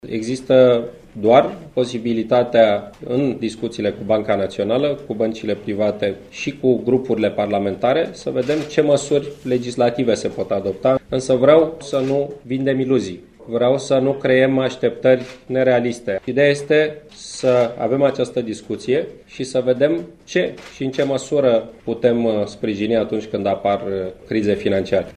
În acest context, premierul Victor Ponta a declarat că trebuie găsite soluţii legale pentru ca, pe viitor, cetăţenii să fie protejaţi de efectele unor crize de pe piaţa financiar-bancară: